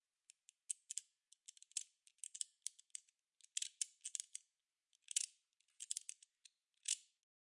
OWI " 手枪装弹
描述：使用磨刀器创建。
Tag: 机械 OWI 愚蠢